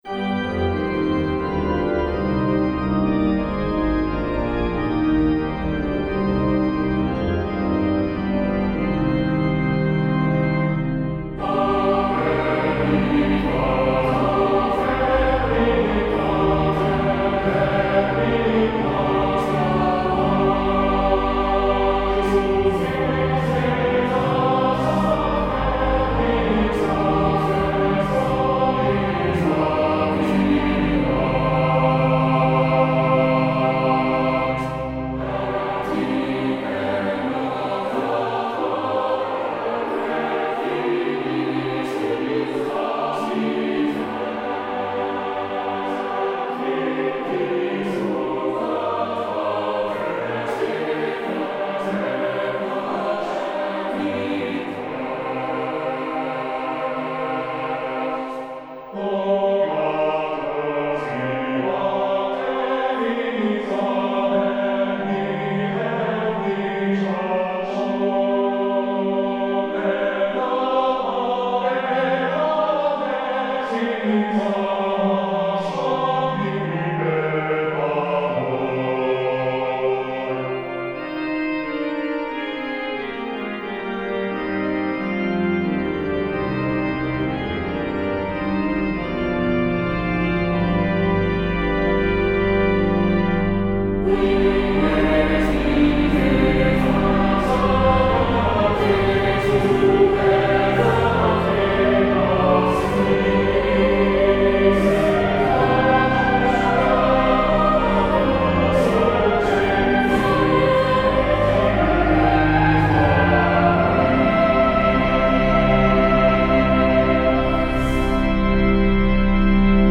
Free descant to the hymn tune BANGOR - 'O very God of Very God'